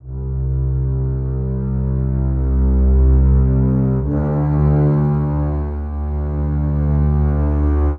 Doing Stuff (Bass) 120BPM.wav